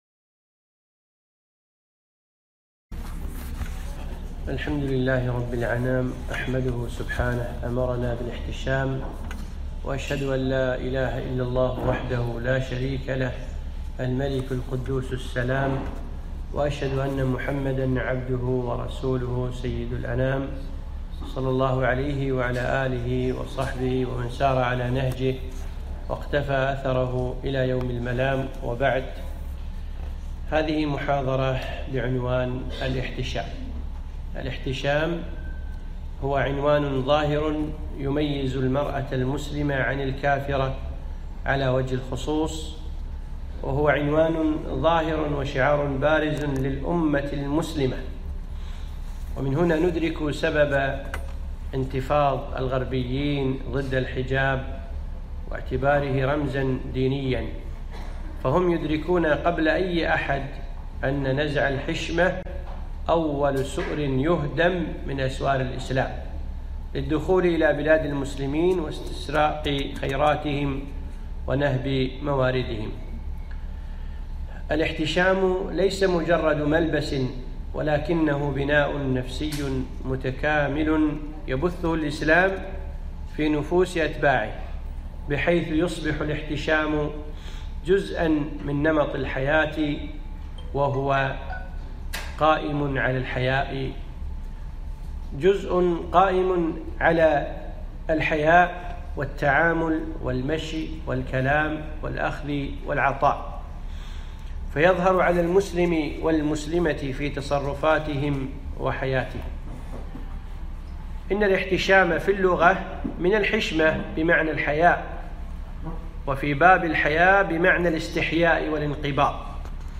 محاضرة - الاحـتشـام